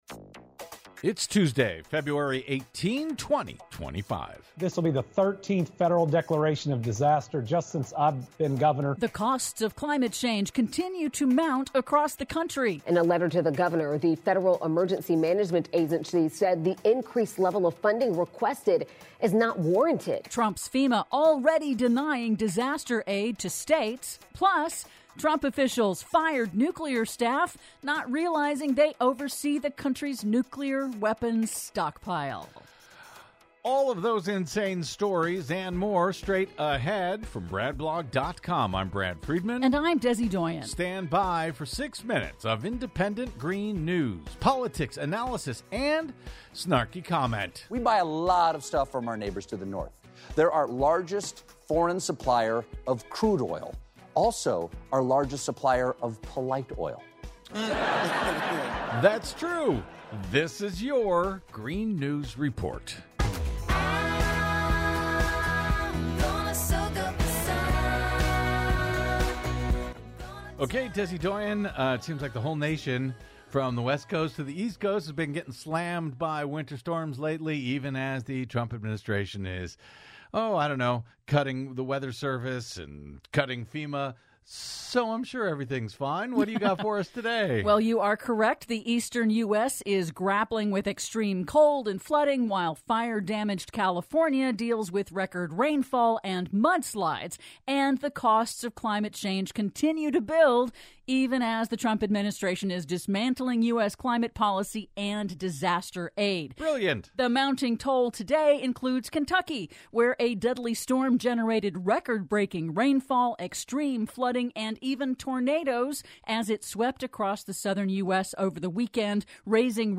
IN TODAY'S RADIO REPORT: The costs of climate change continue to mount, as extreme weather strikes across the U.S.; Trump Administration's FEMA already denying disaster aid, even to 'red' states; PLUS: Trump officials fired nuclear staff that oversee the country's nuclear weapons stockpile... All that and more in today's Green News Report!